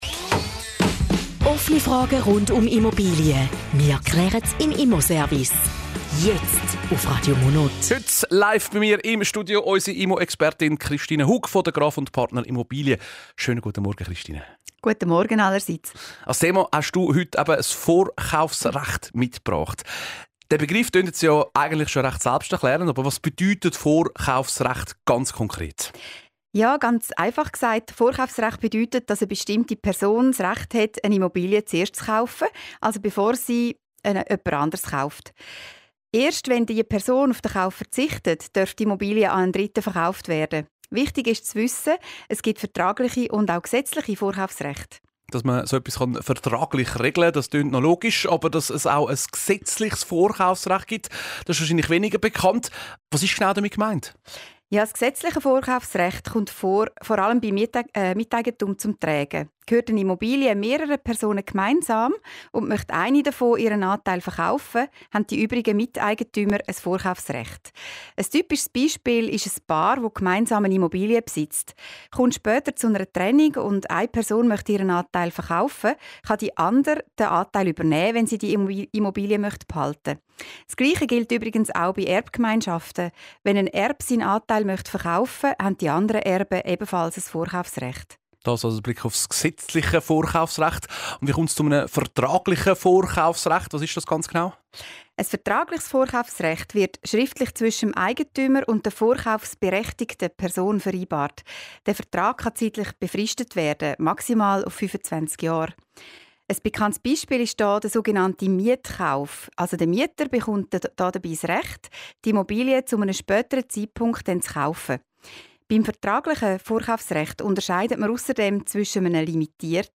Zusammenfassung des Interviews zum Thema "Vorkaufsrecht":